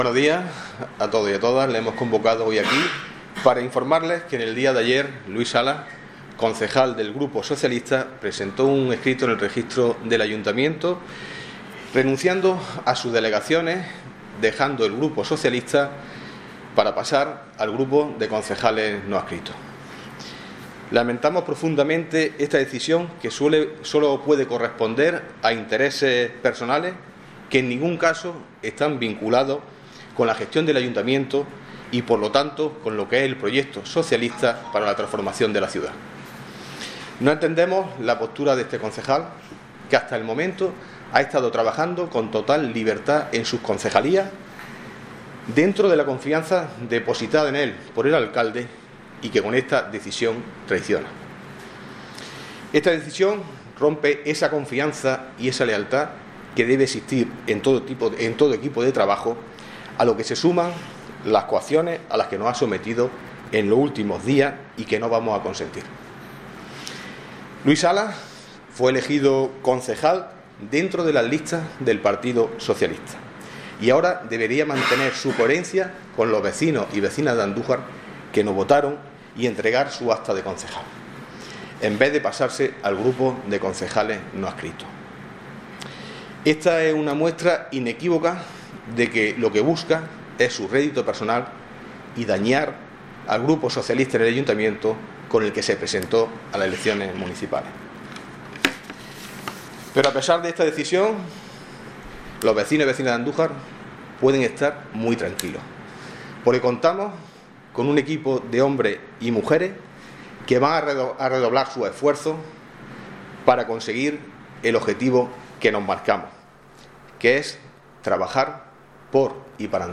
Así lo ha anunciado el Concejal de Presidencia Pedro Luis Rodríguez en una comparecencia pública en la que ha cargado duramente contra Salas acusándole de traición y apuntando a intereses de índole personal en su deseo de «dañar al grupo municipal socialista».
Audio comparecencia del Concejal de Presidencia: